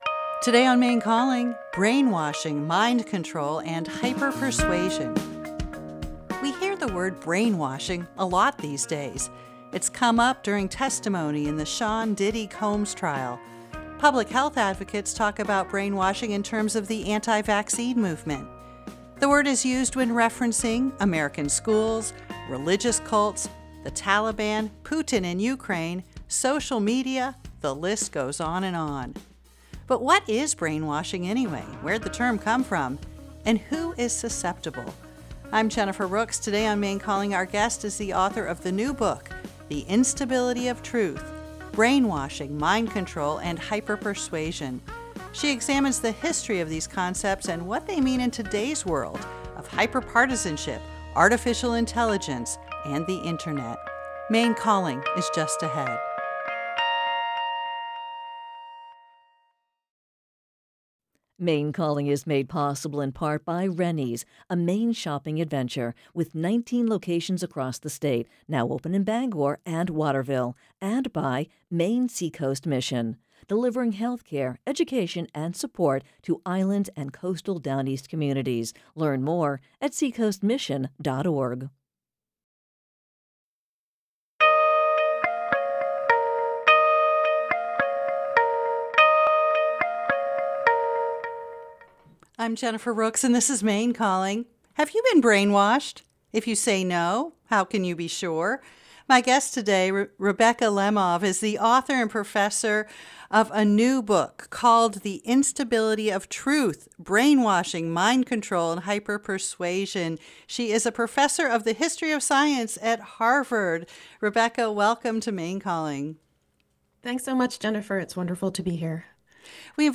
Maine Calling is a live, call-in radio program offering enlightening and engaging conversations on a wide range of topics.